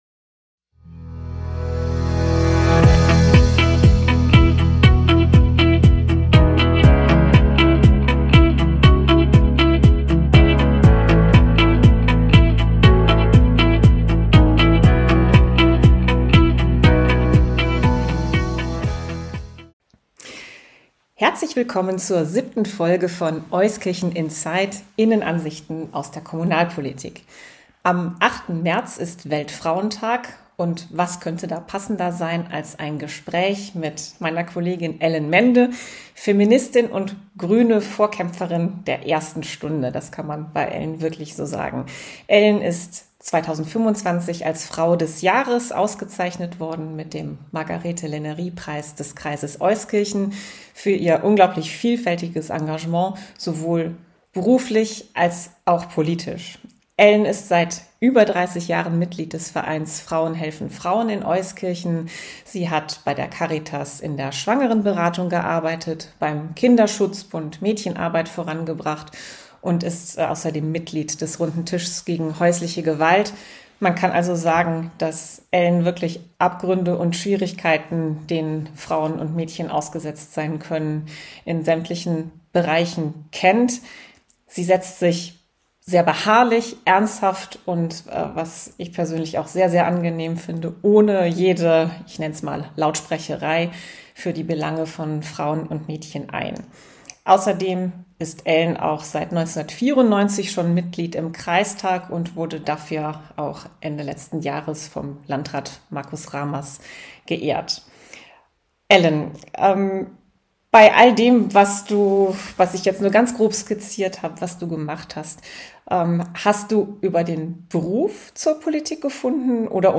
Anlässlich des Weltfrauentags ein Gespräch